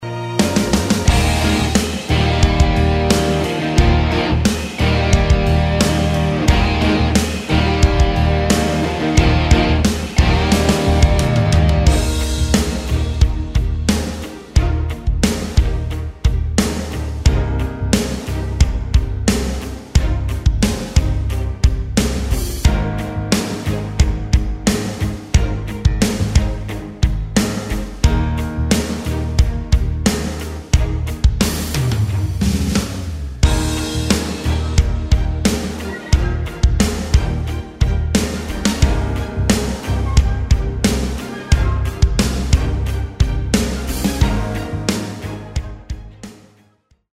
Instrumental
backing track